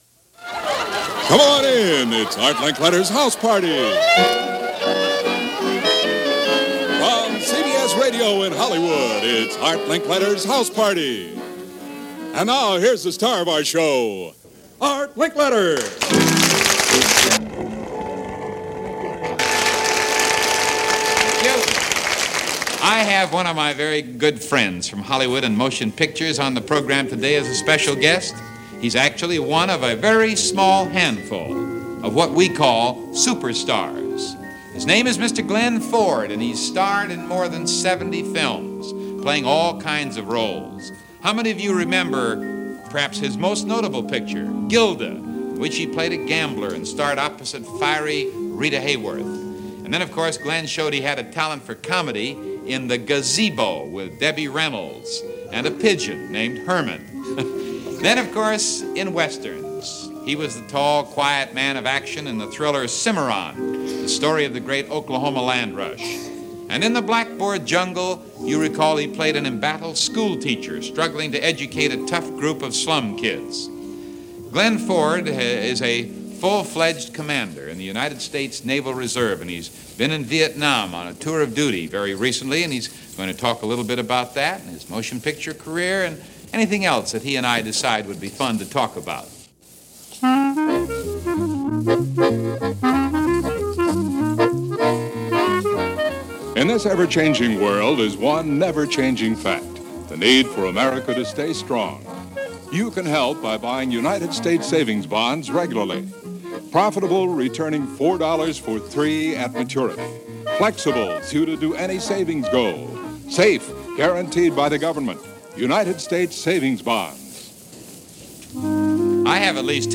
March 9, 1967 - Art Linkletter's House Party - Past Daily Pop Chronicles - a daily show, one of the last from network radio before it ended in October 1967.
It featured everything from household hints to hunts for missing heirs. The show usually began with a humorous monologue by Linkletter, often followed by an audience participation quiz to win prizes, musical groups (not rock n’ roll of the mid-late 60s variety), informal celebrity interviews and guest speakers from assorted walks of life.